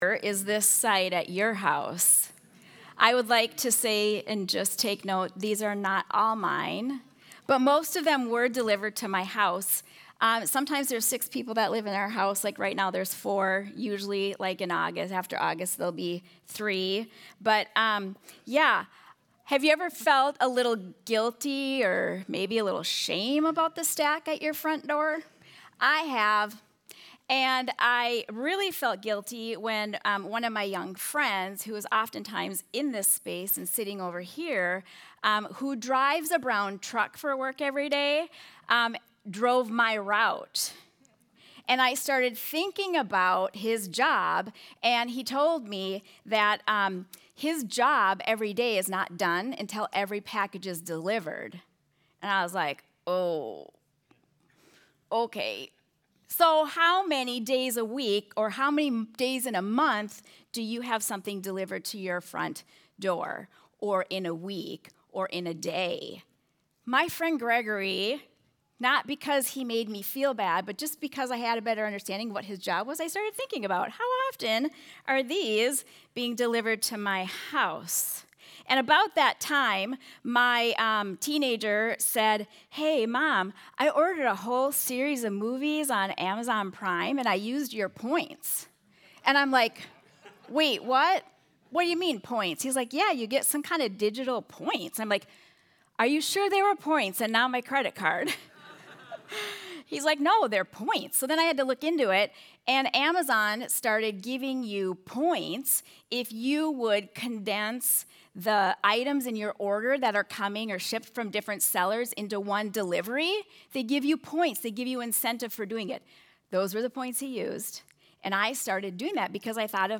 Sunday Sermon: 7-27-25